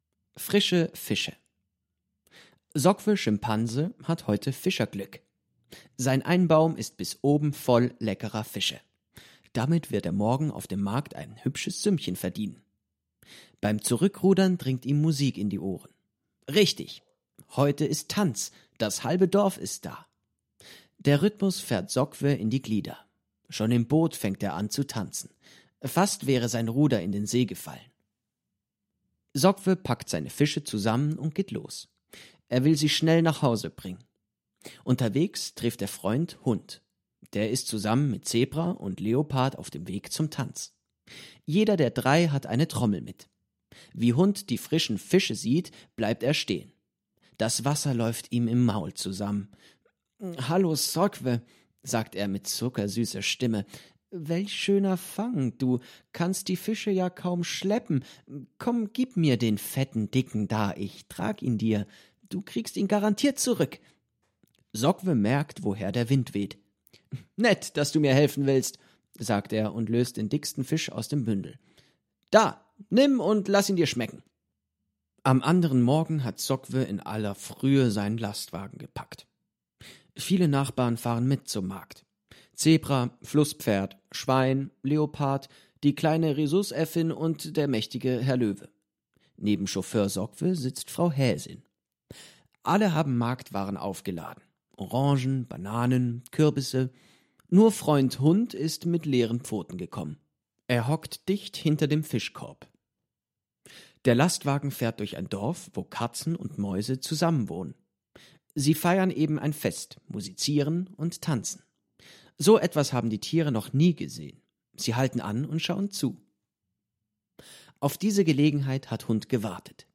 → Mehr zum BuchWeitere Materialien zum Buch→ Lesung Deutsch (MP3)→ Ein Kamishibai (Koffertheater) ist in der Schweiz bei Bibliomedia erhältlich.